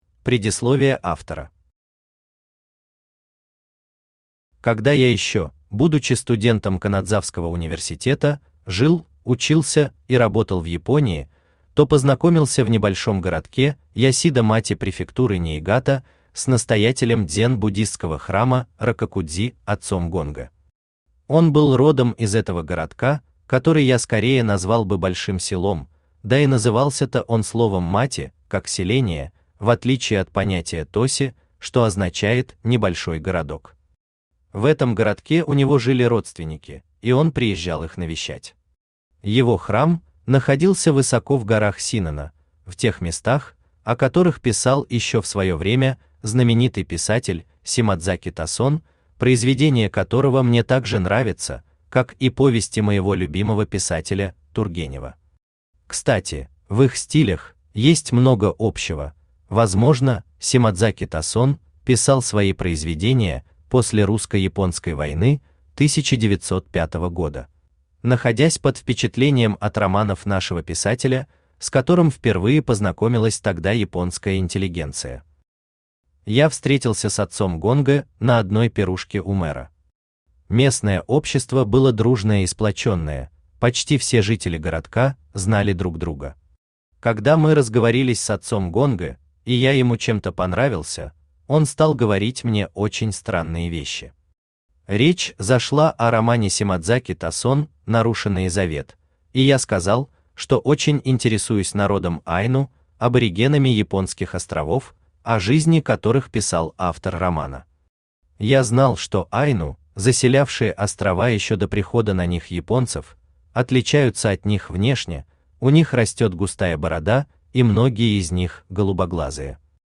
Аудиокнига Будда и Моисей | Библиотека аудиокниг
Aудиокнига Будда и Моисей Автор Владимир Фёдорович Власов Читает аудиокнигу Авточтец ЛитРес.